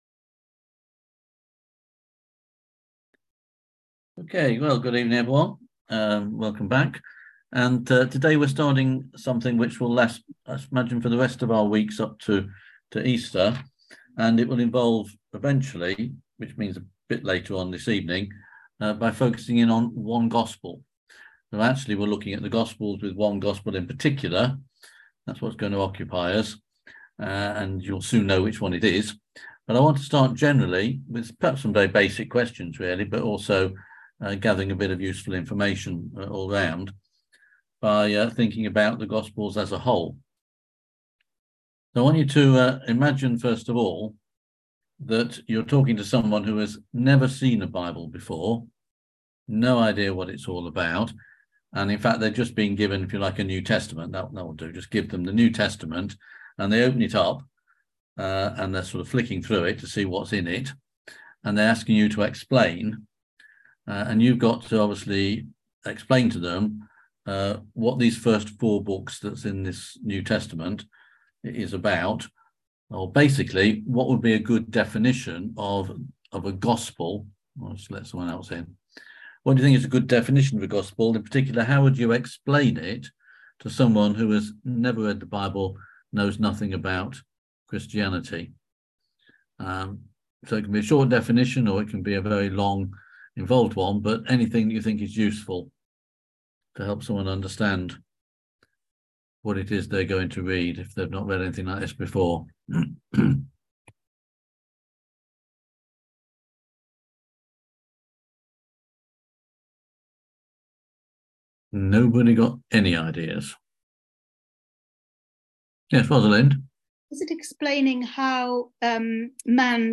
On February 9th at 7pm – 8:30pm on ZOOM